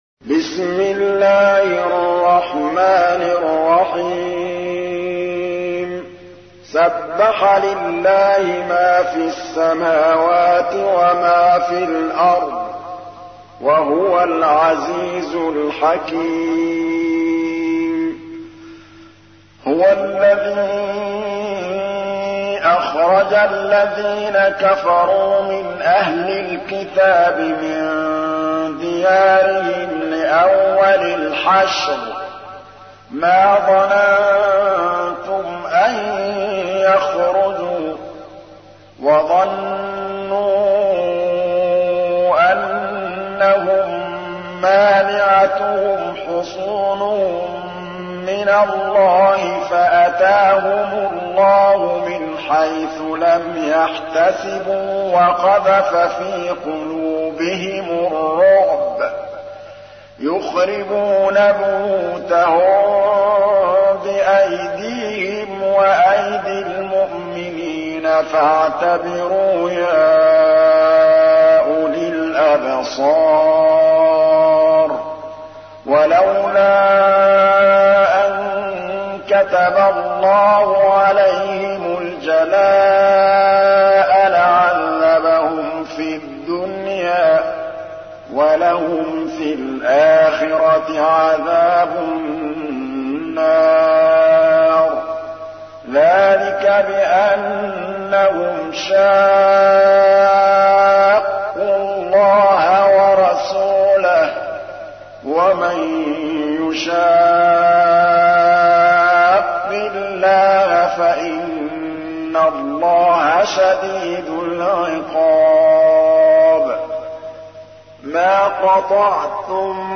تحميل : 59. سورة الحشر / القارئ محمود الطبلاوي / القرآن الكريم / موقع يا حسين